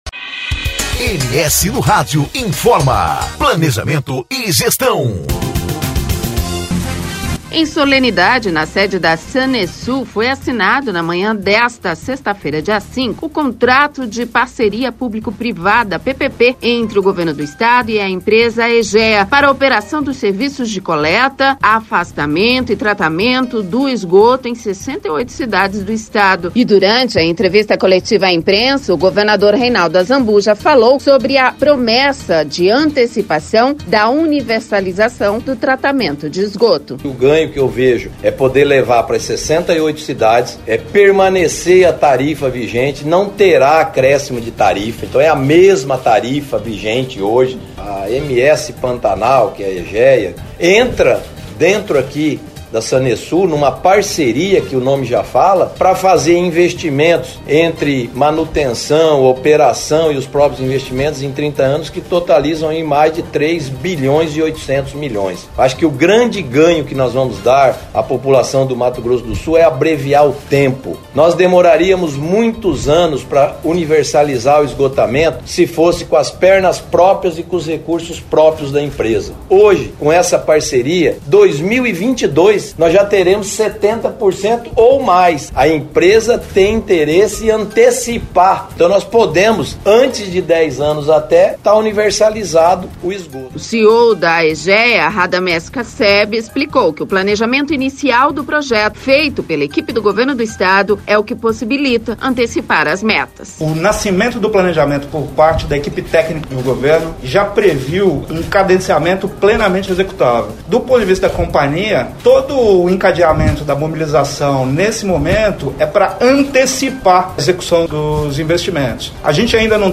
E durante entrevista coletiva à imprensa o governador Reinaldo Azambuja, falou sobre a promessa de antecipação da universalização do tratamento de esgoto.